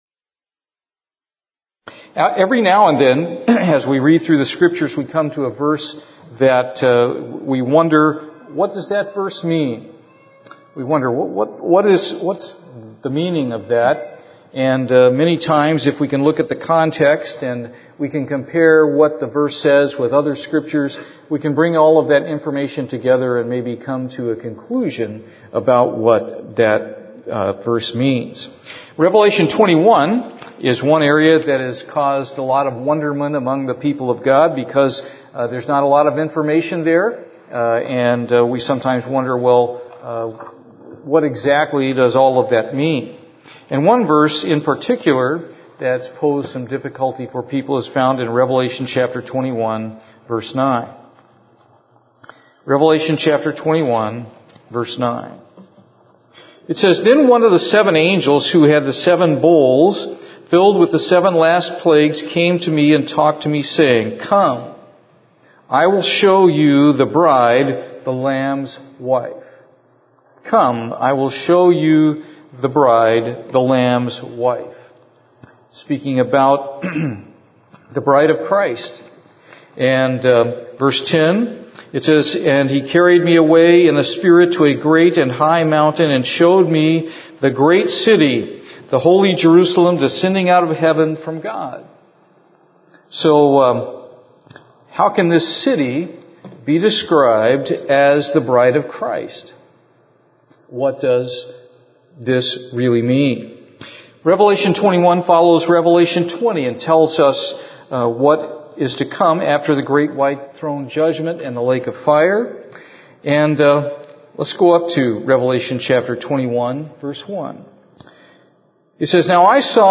Given in Houston, TX